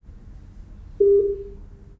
Binaural recordings of 400 Hz pure-tones played from a loudspeaker in my dining room.
Below, for instance, are recordings for 0° azimuth, cropped 1 second before and after the recorded tone’s onset.
Pure-tones (400 Hz) with 10 ms on/off ramps (Hanning) were played from a single loudspeaker in my dining room well above average ambient noise levels (~36 dB).